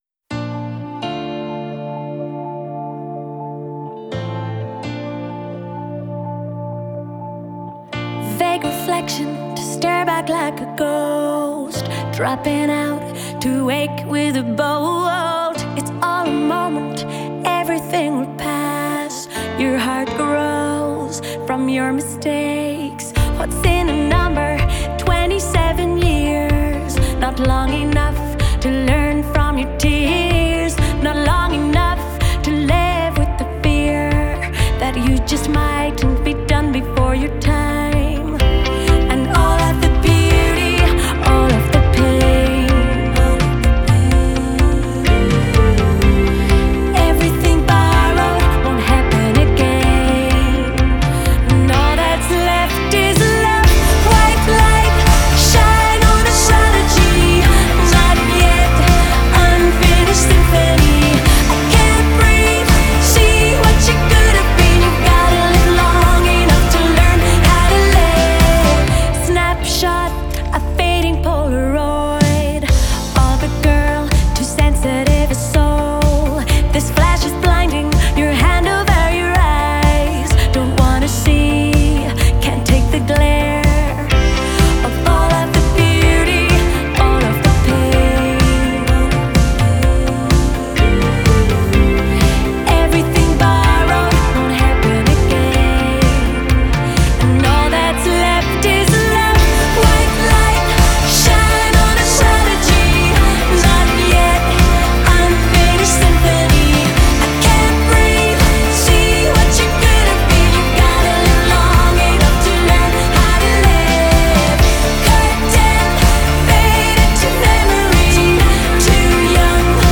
Genre: Pop, Pop Rock